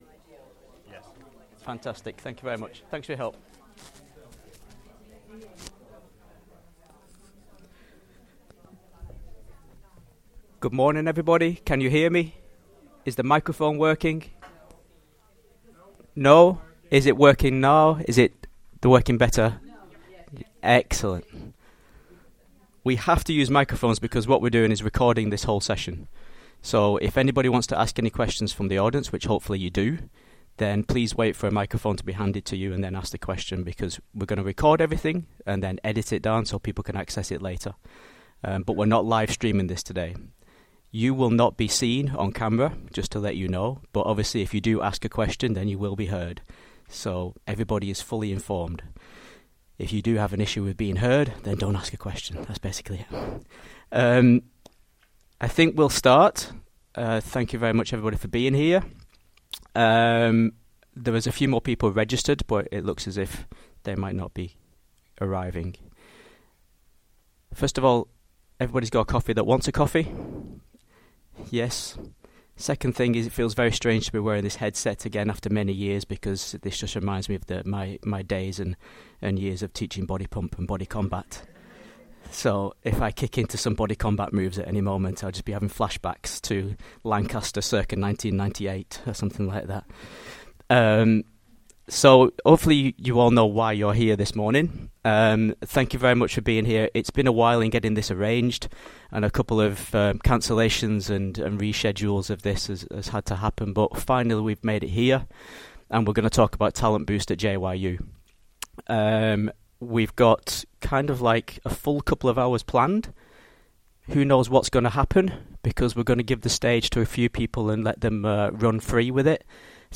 The JYU Talent Boost team held a project information session for the staff of the university on the 13th September 2023.